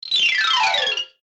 MG_sfx_vine_game_fall.ogg